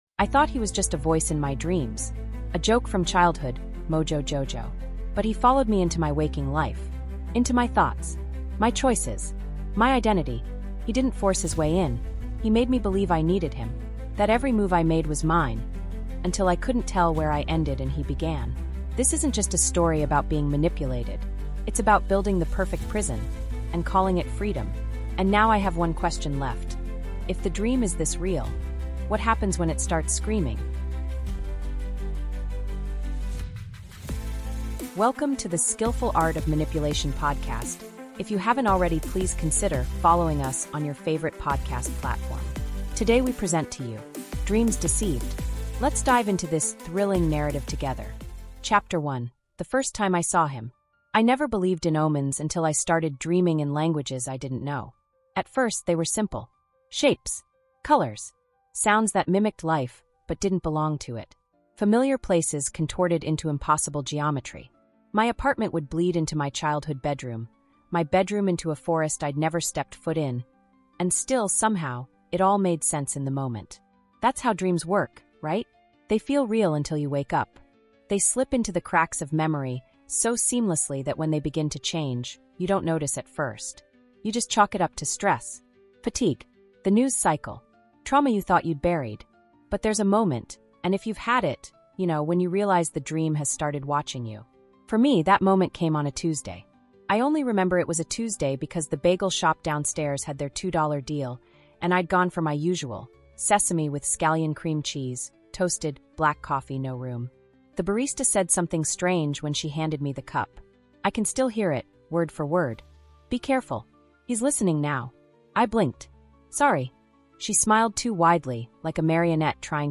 Told in raw, intimate first-person narration, this 7-chapter psychological thriller follows one person’s unraveling after a childhood villain — Mojo Jojo — begins appearing in their dreams… and then in their waking life. What starts as eerie coincidence becomes a full-scale mental takeover, as Mojo Jojo weaves himself into the narrator’s choices, memories, and reality.